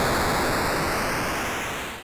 wizard_death.wav